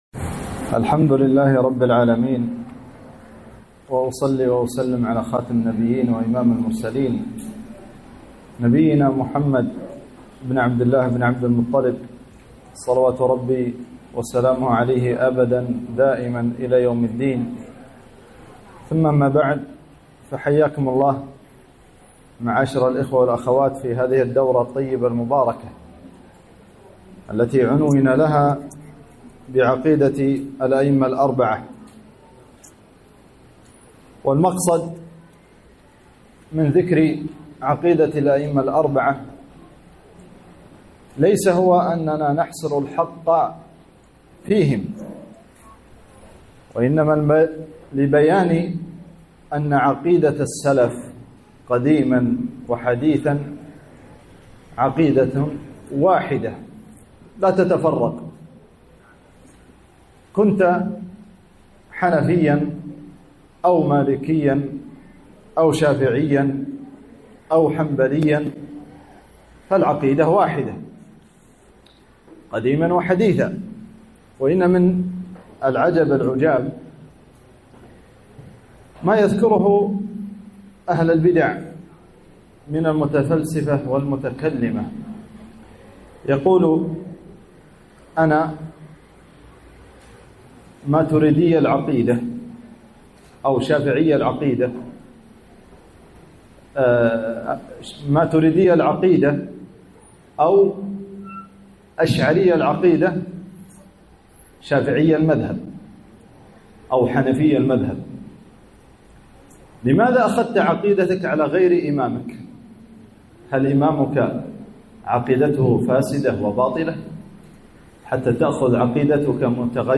محاضرة - عقيدة السلف (في تايلندا)